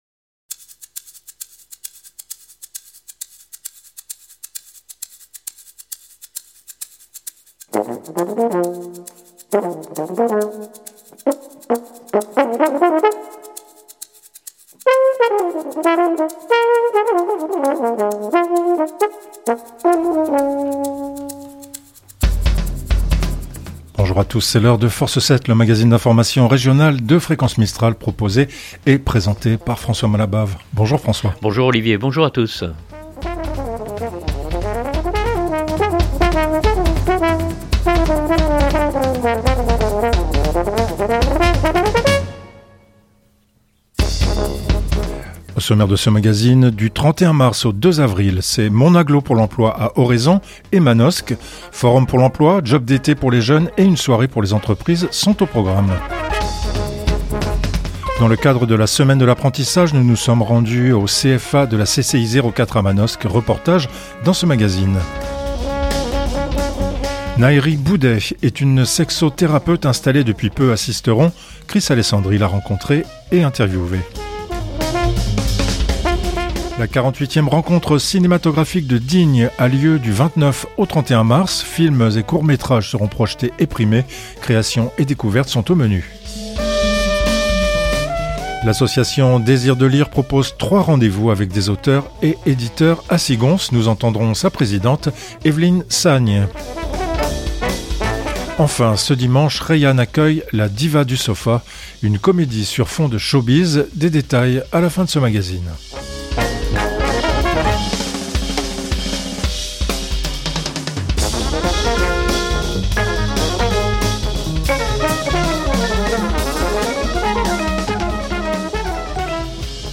Reportage dans ce magazine.